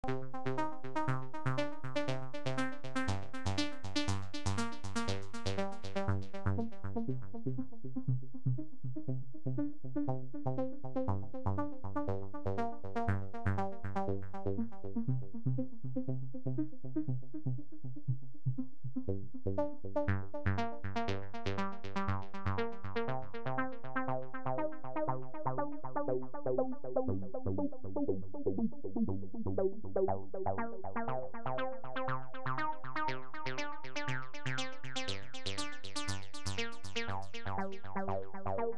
Soft Synth